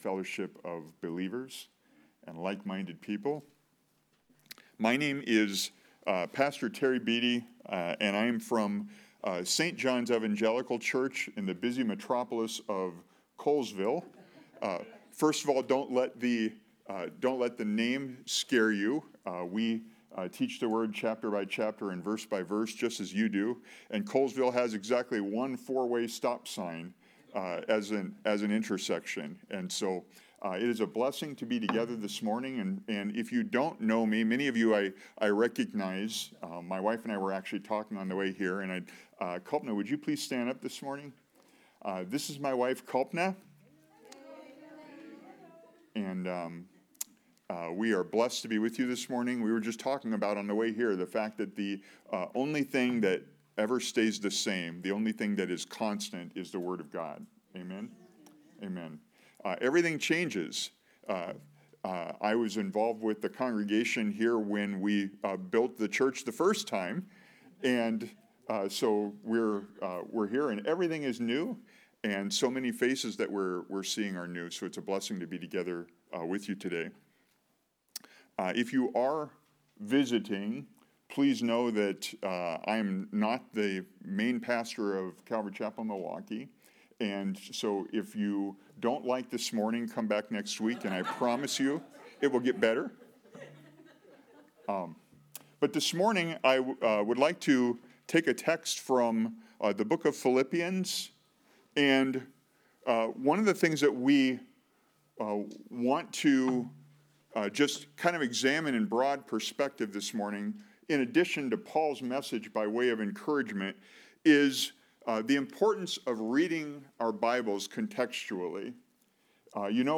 Sermons | Calvary Chapel Milwaukee